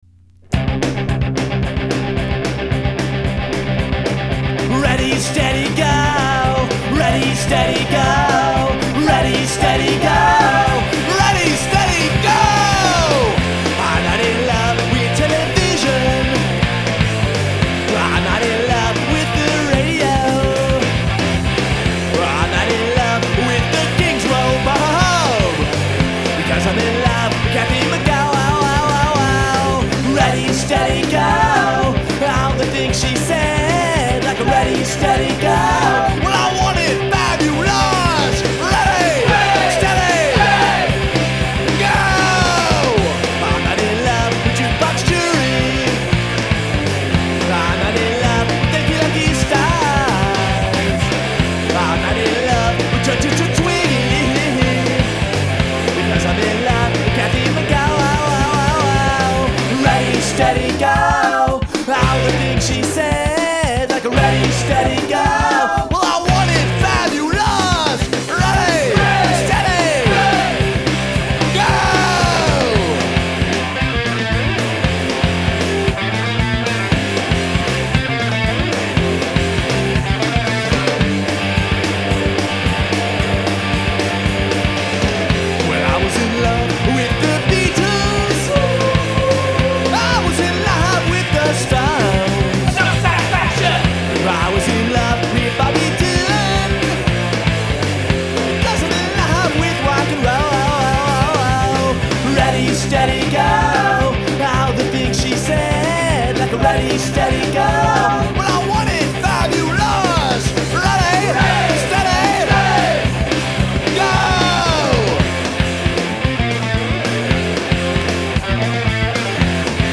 Pop-Punk